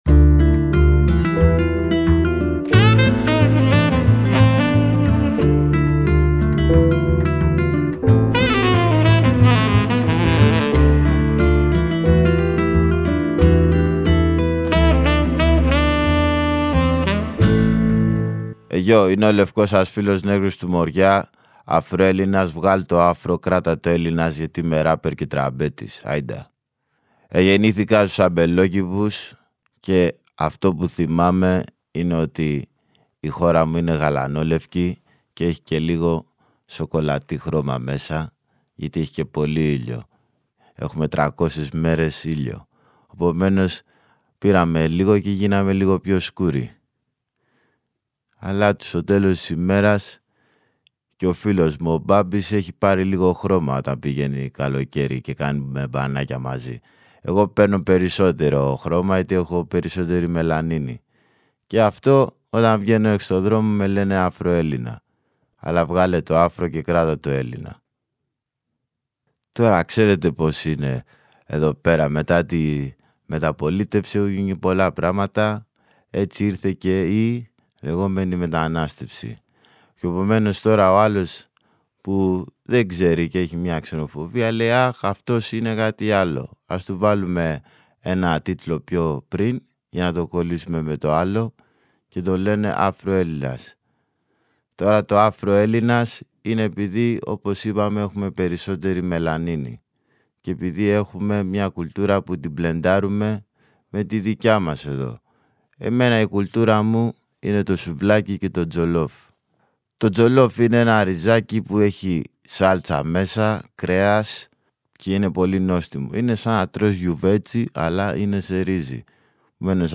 Στο πλαίσιο της συνεργασίας του Μουσείου Μπενάκη με την ΕΡΤ και το Κosmos 93,6, ηχογραφήθηκε, ειδικά για την έκθεση, μια σειρά προσωπικών αφηγήσεων μερικών από τους μουσικούς που συμμετέχουν στο ηχοτοπίο ΗΧΗΤΙΚΕΣ ΔΙΑΣΤΑΣΕΙΣ ΑΦΡΙΚΑΝΙΚΗΣ ΔΙΑΣΠΟΡΑΣ.